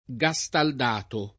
gastaldato [ g a S tald # to ]